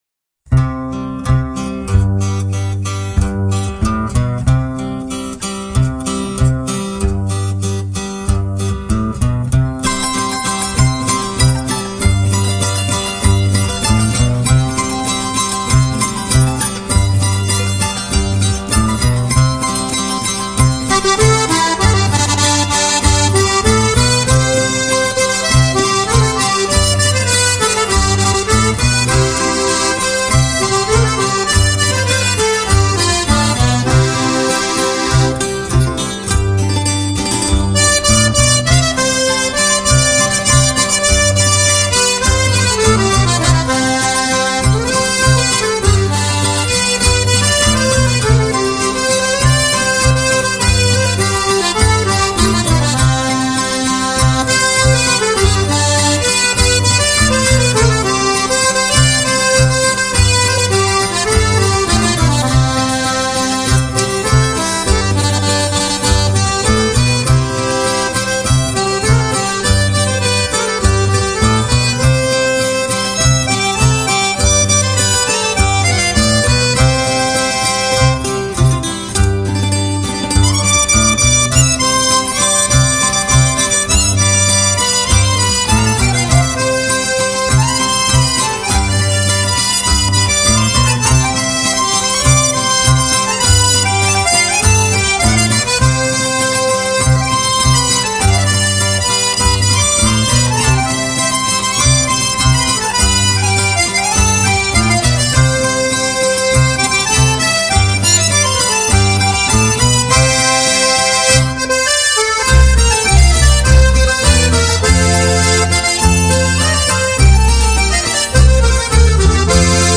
Жанр: Instrumental pop / Accordeon